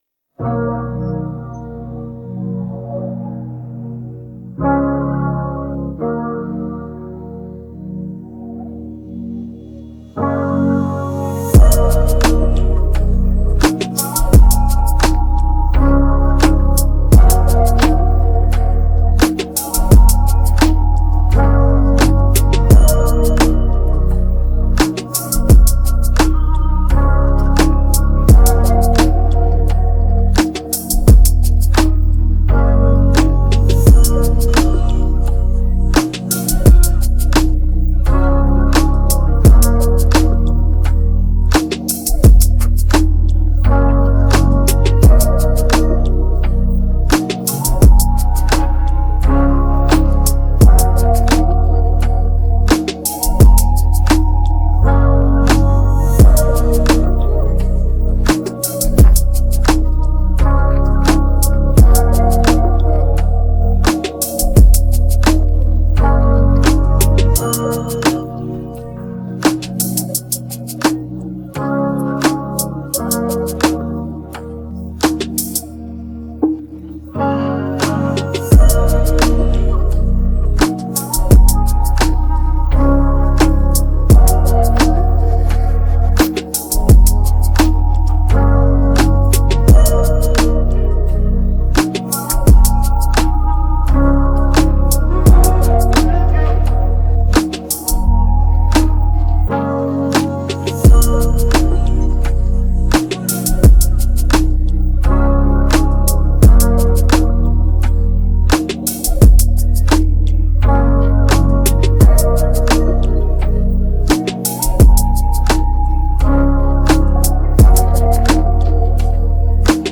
Afro popAfrobeatsHip hop